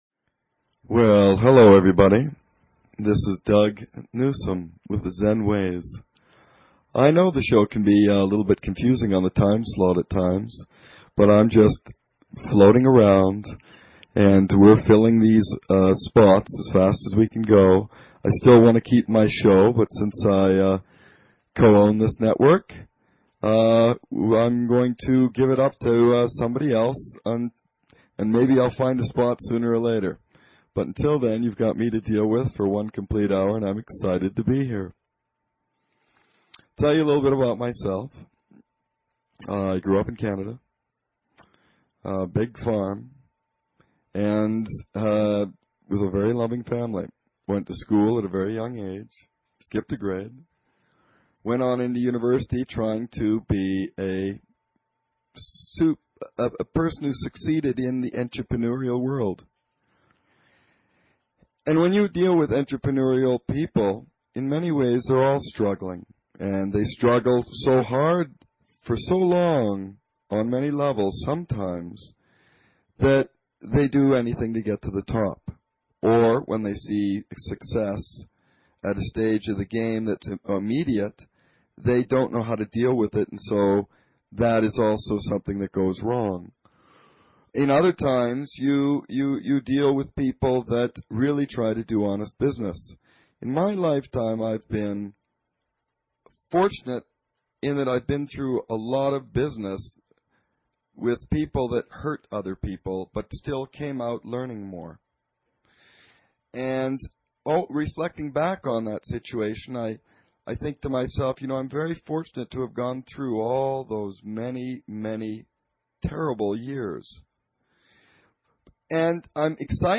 Talk Show Episode, Audio Podcast, Zen_Ways and Courtesy of BBS Radio on , show guests , about , categorized as